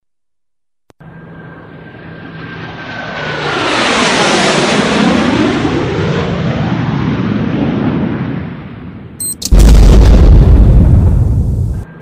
blast-in-jet_24791.mp3